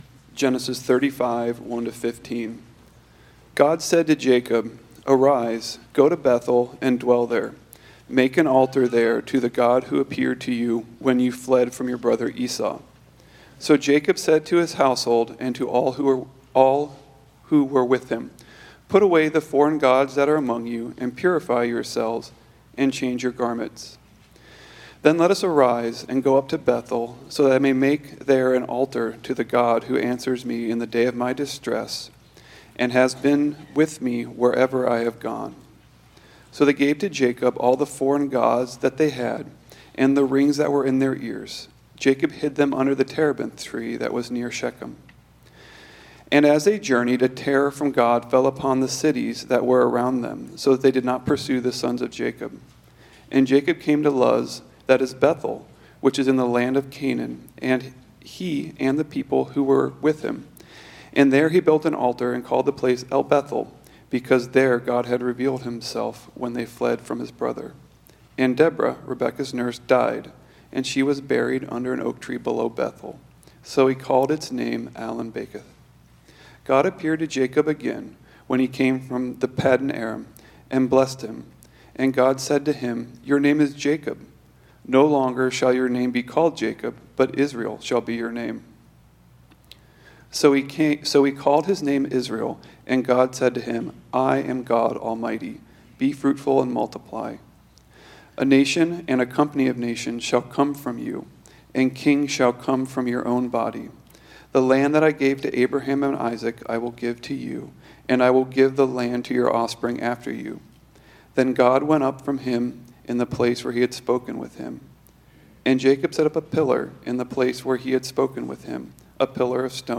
Sermons | Staunton Alliance Church